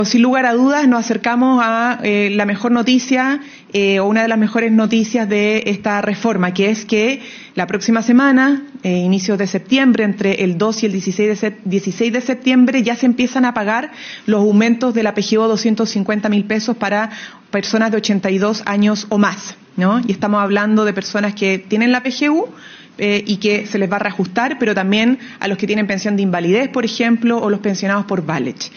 La ministra vocera de Gobierno, Camila Vallejo, destacó que este es el inicio de los beneficios concretos de la reforma, cuya implementación será gradual.